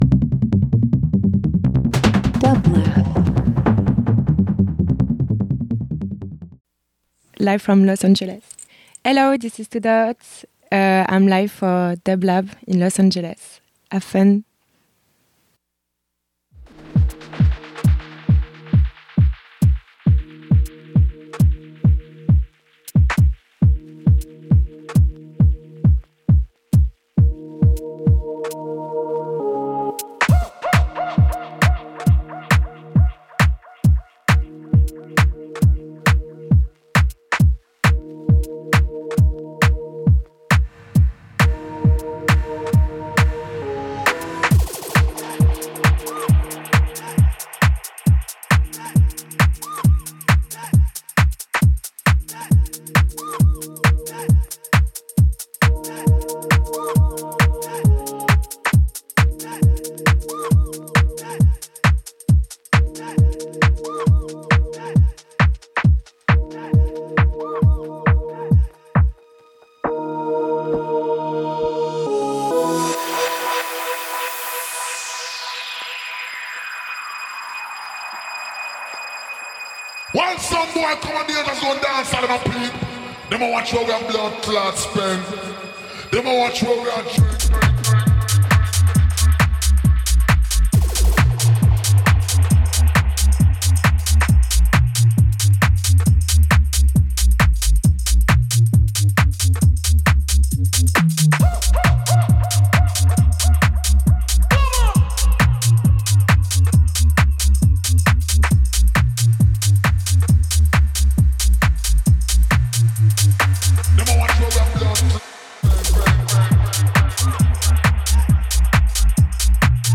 Bass Electronic Techno